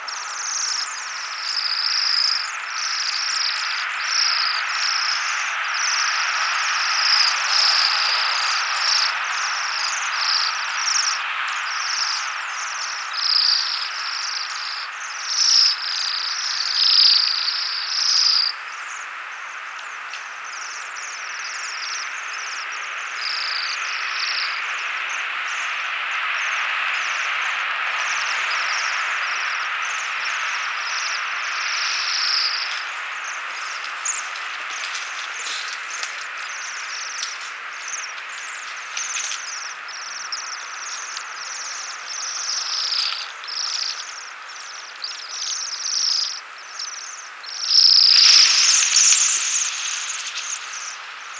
Bohemian Waxwing Bombycilla garrulus
Flight call description A high, sharply trilled "zeeee". Also a high, relatively short, pure-toned, descending "tseew".
Perched flock that takes off.